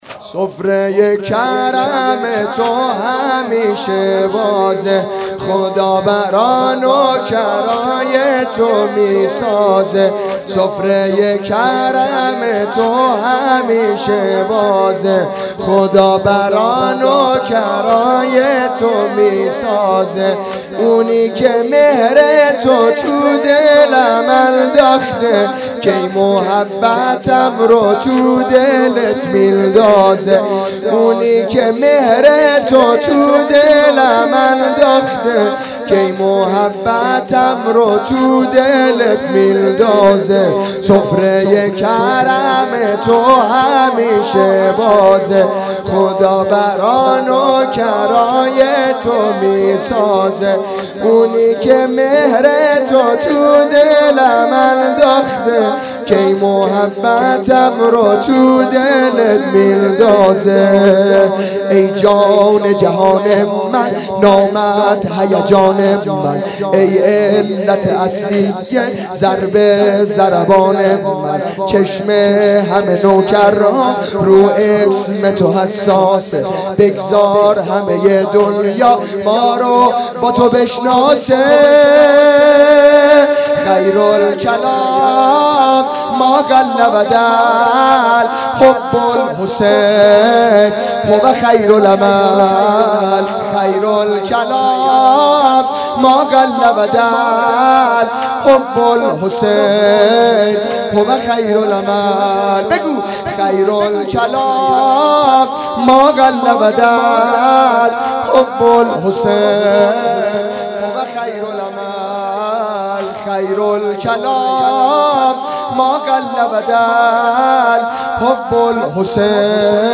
سفره کرم تو همیشه بازه _ واحد
اقامه عزای رحلت پیامبر اکرم و شهادت امام حسن مجتبی علیه السلام